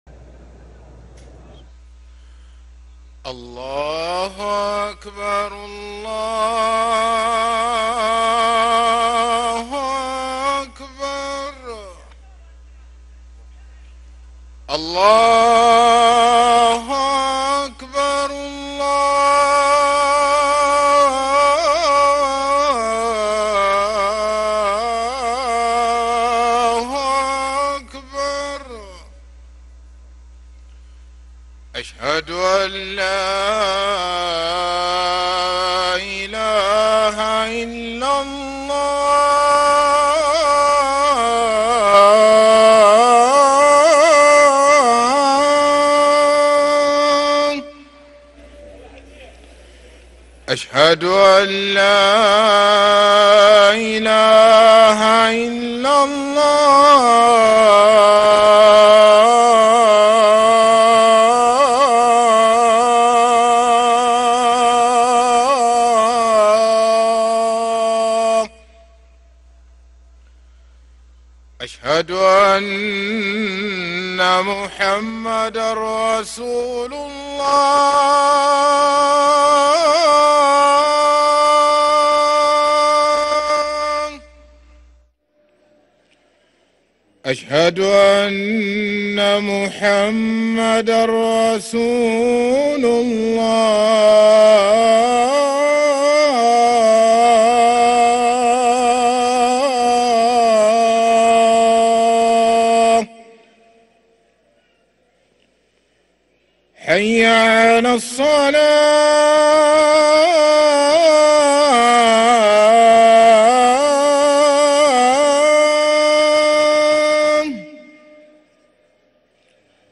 أذان المغرب للمؤذن